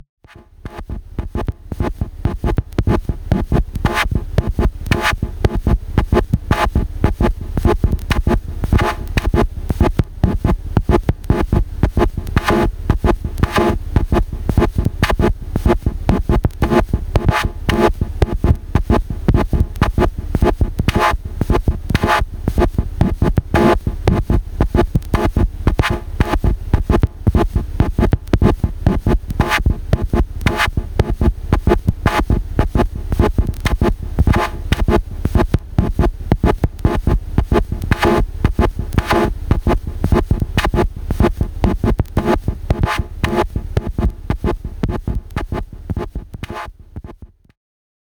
Just recorded a quick example where I tried to modulate the envelope of hht which is far from an ideal solution but it opened new doors for me. The audio is from the hht module.
That hh audio is swishy